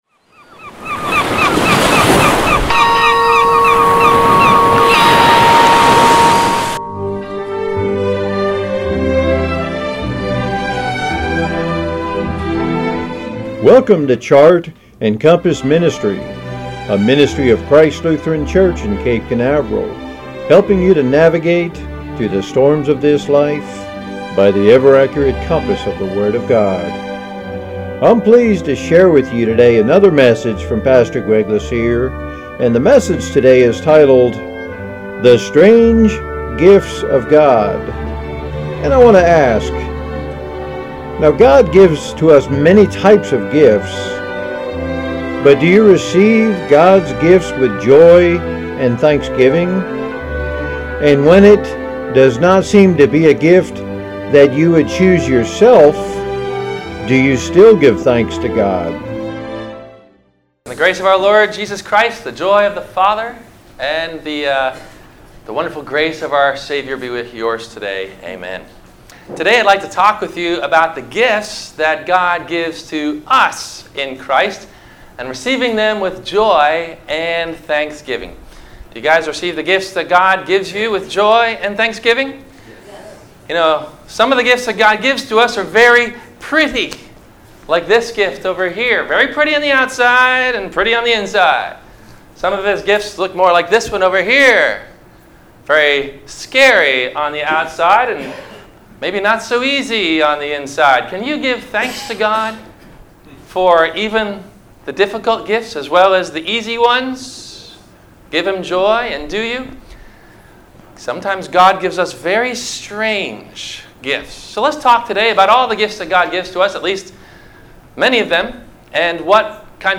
The Strange Gifts of God – WMIE Radio Sermon – January 07 2018 - Christ Lutheran Cape Canaveral